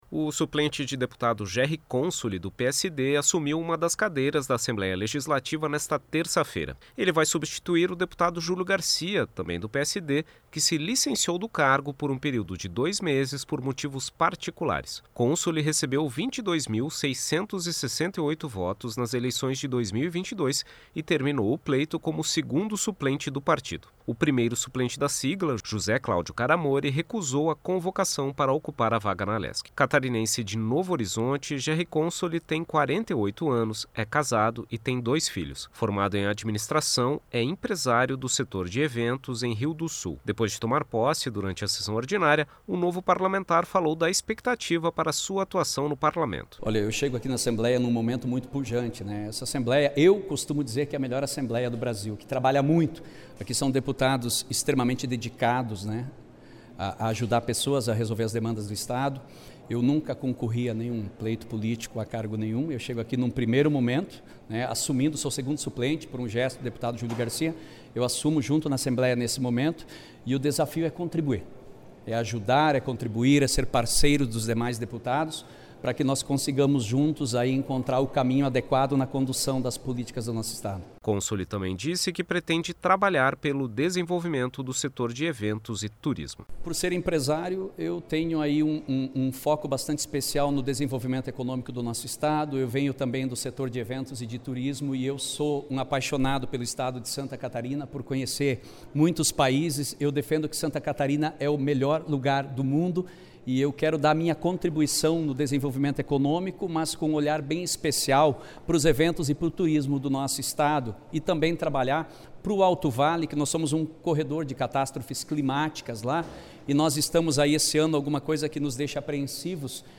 Entrevista com:
- deputado Gerri Consoli (PSD).